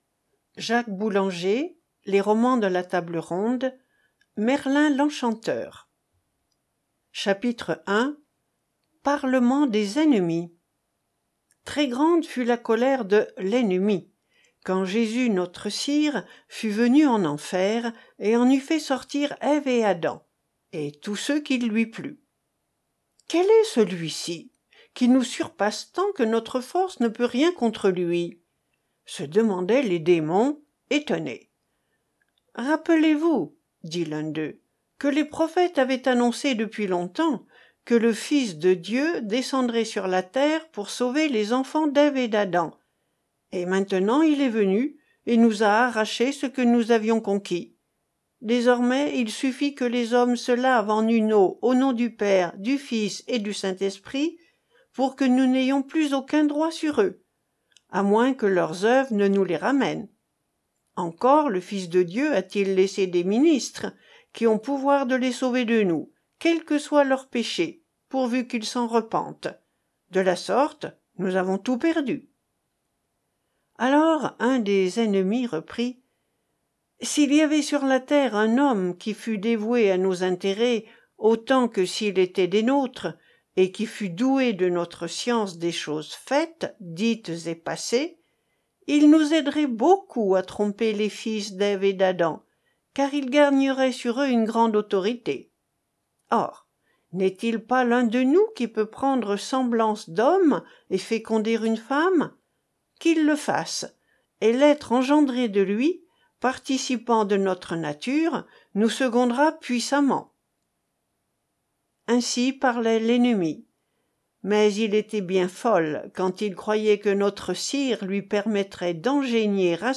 Genre : Romans